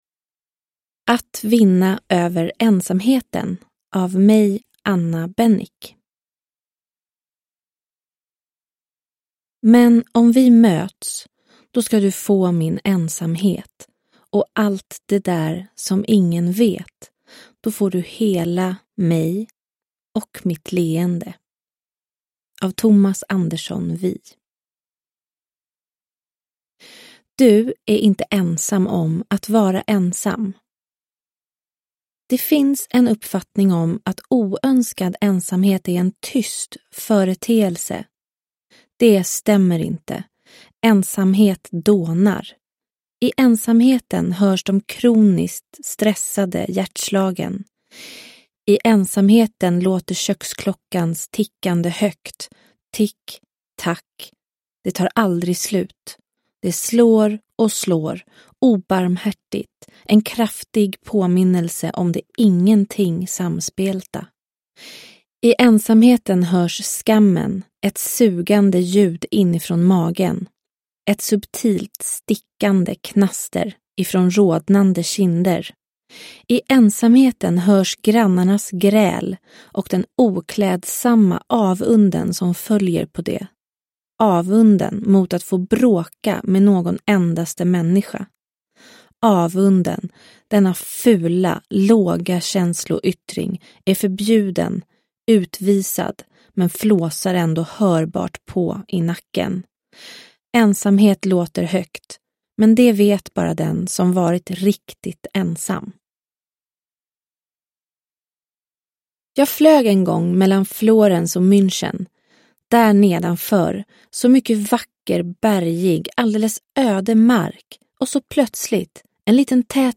Att vinna över ensamheten : psykologens bästa råd – Ljudbok – Laddas ner